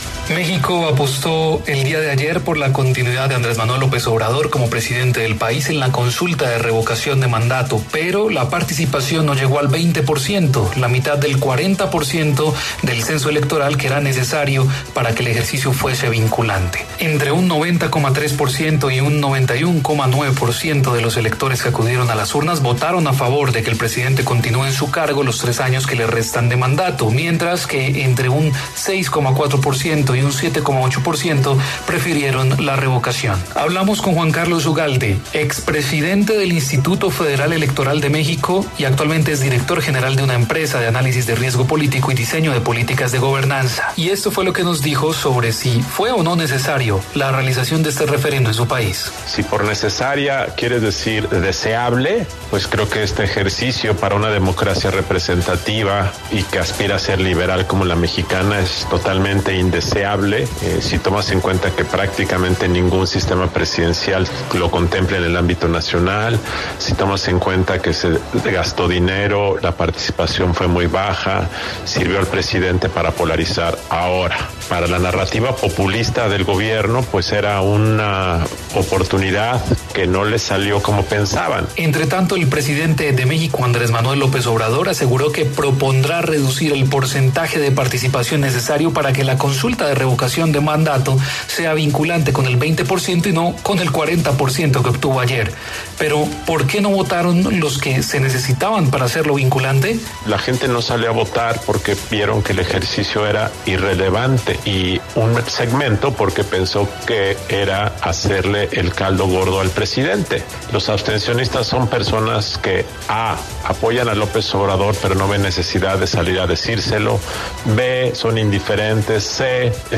Juan Carlos Ugalde, expresidente del Instituto Federal Electoral de México habló sobre la poca participación que tuvo la consulta de revocación de mandato de Andrés Manuel López Obrador, presidente de México.